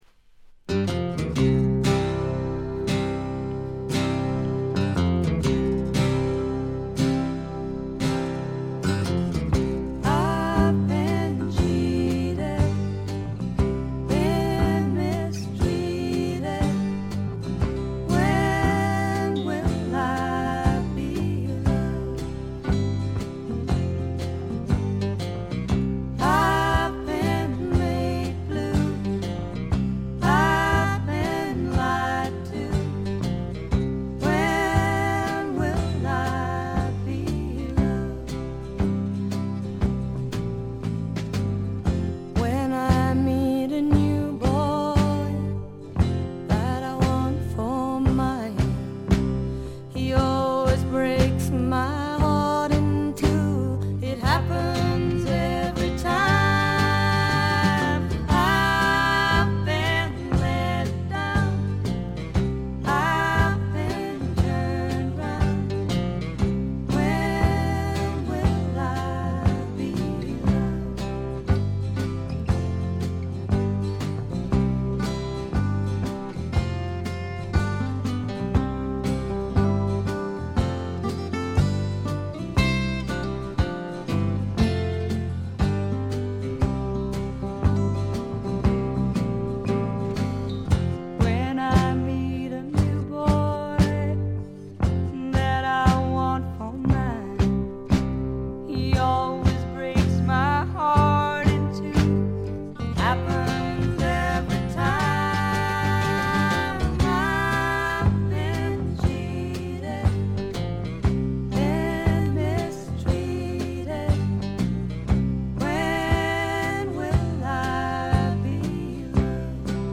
部分試聴ですが軽微なチリプチ少し。
試聴曲は現品からの取り込み音源です。